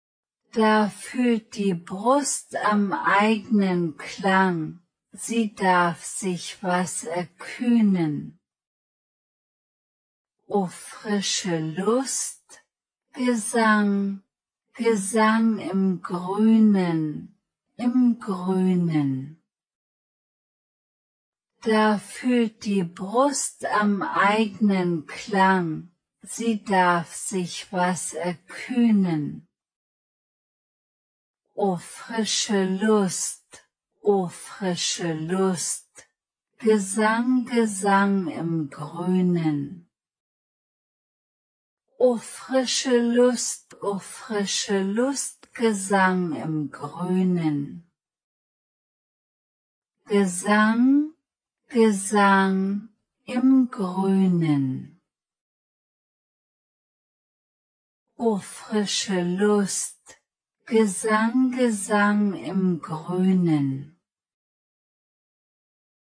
voix femme(52-81)
im-wald-prononce-fille-52-81.mp3